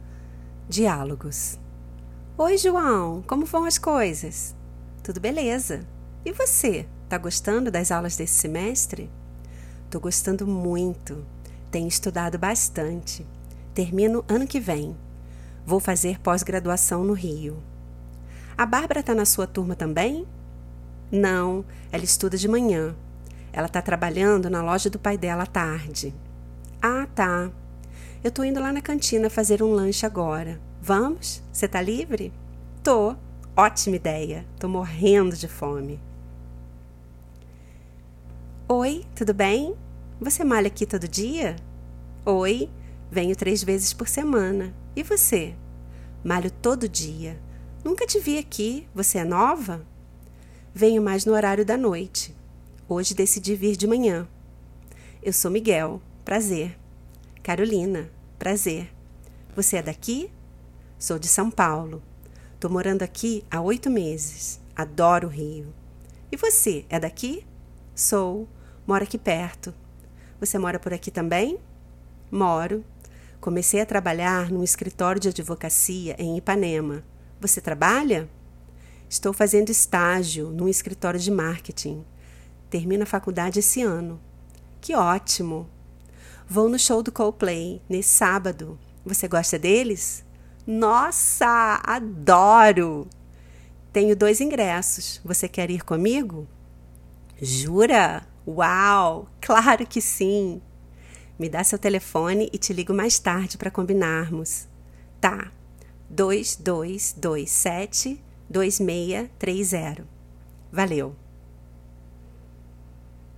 Dialogues (college / gym) - Tudo Bem? Brazilian Portuguese